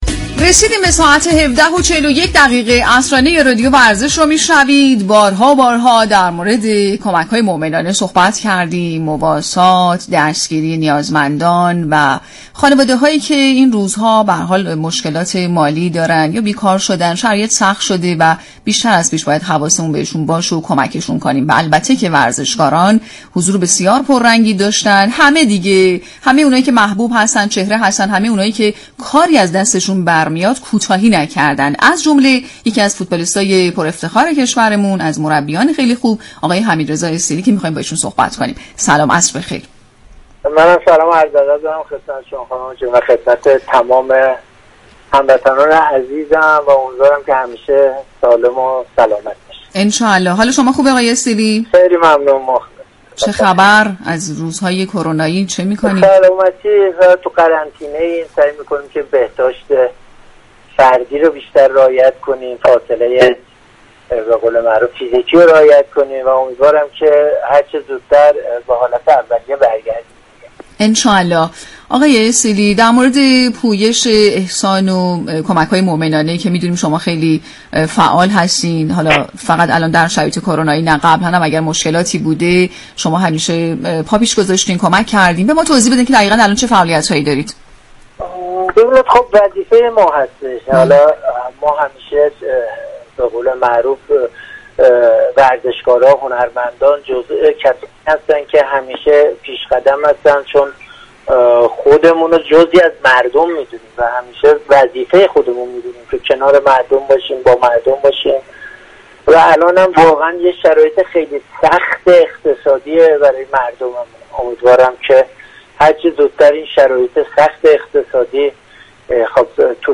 شما می توانید از طریق فایل صوتی پیوست شنونده بخشی از برنامه رادیو ورزش كه به توضیح درباره نحوه فعالیت های این ورزشكار در پویش همدلی و مواسات و ورزشكاران همدل از زبان حمید استیلی می پردازد؛ باشید.